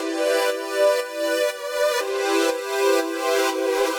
Index of /musicradar/french-house-chillout-samples/120bpm
FHC_Pad C_120-E.wav